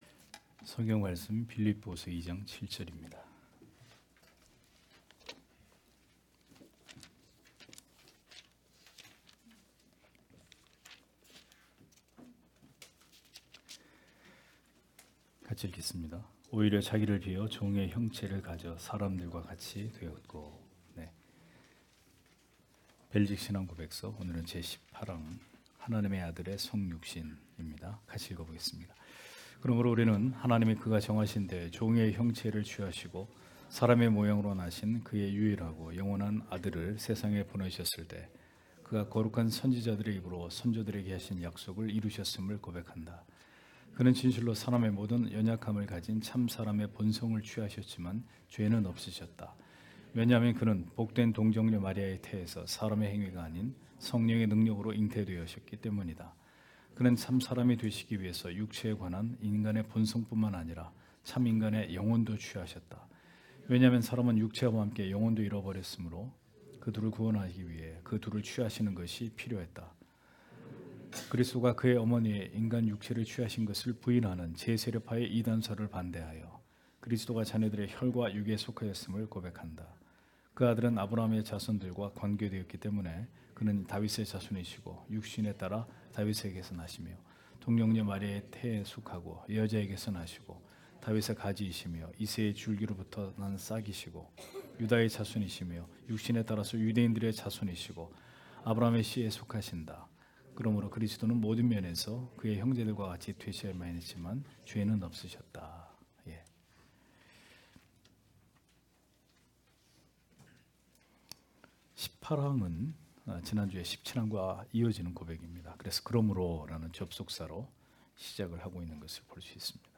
주일오후예배 - [벨직 신앙고백서 해설 19] 제18항 하나님의 아들의 성육신 (빌 2장7절)